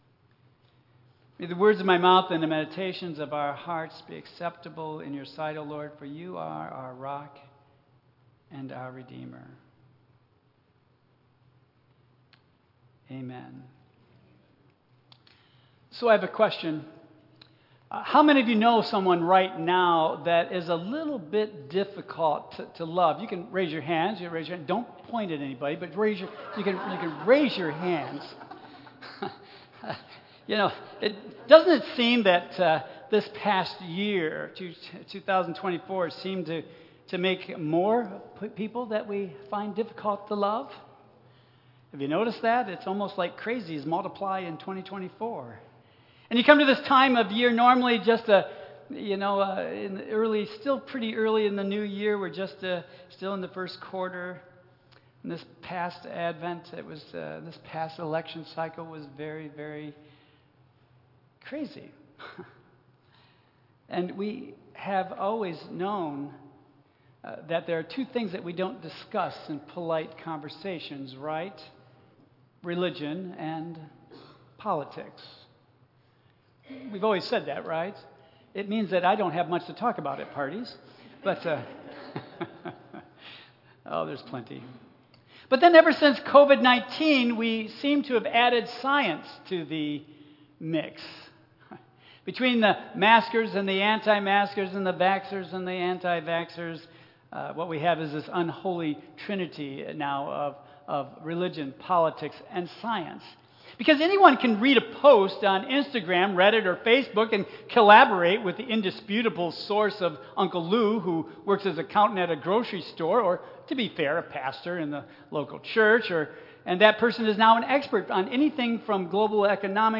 Tagged with Michigan , Sermon , Waterford Central United Methodist Church , Worship